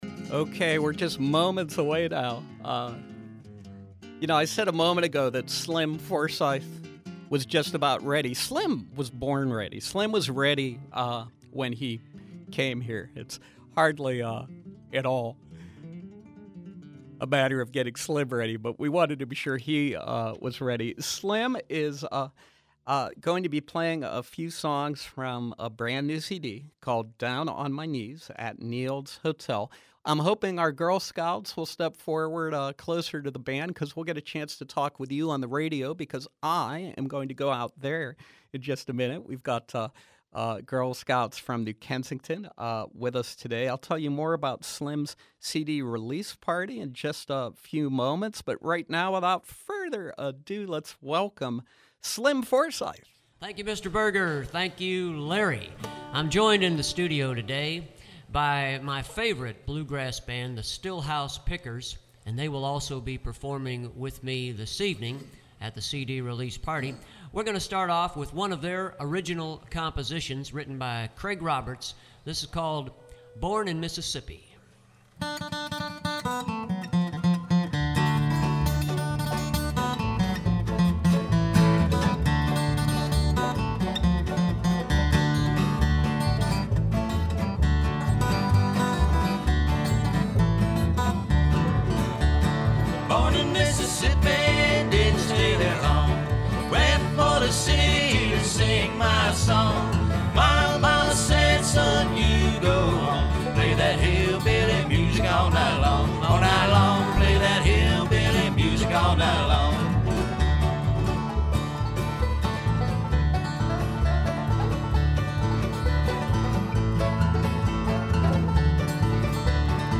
performing his energized brand of country-swing